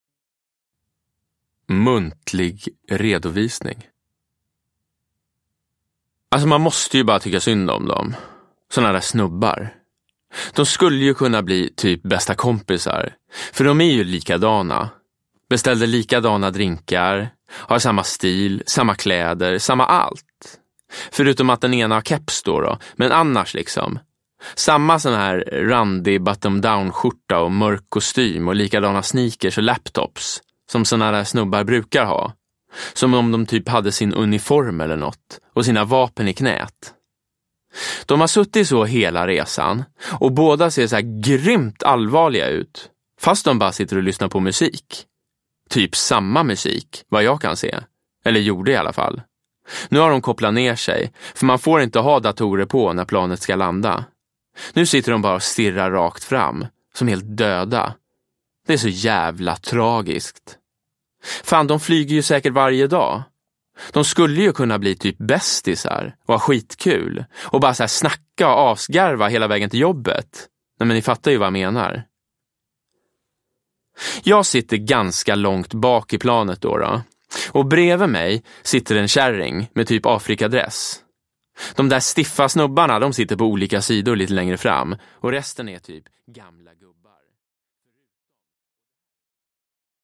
Med ett absolut gehör i dialogerna och en blick för de små detaljerna läser Jonas Karlsson skruvat, roligt, dråpligt, drivet.
Uppläsare: Jonas Karlsson